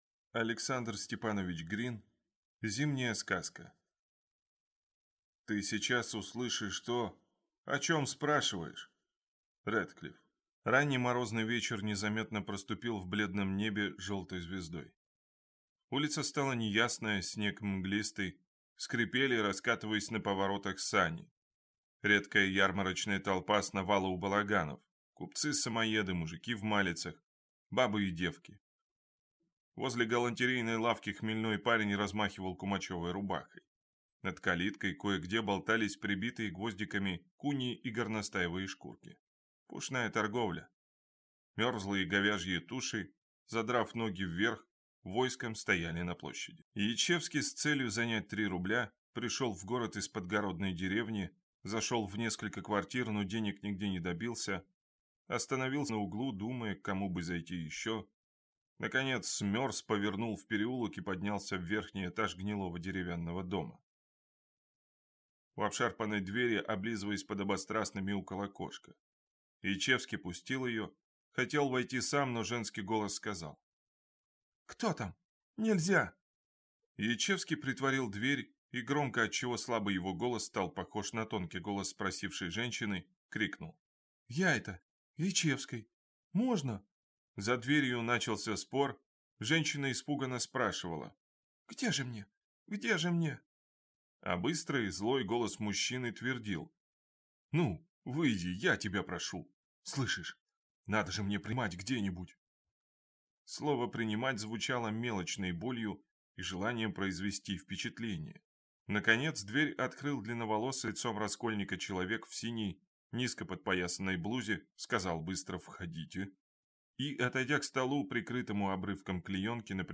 Аудиокнига Зимняя сказка | Библиотека аудиокниг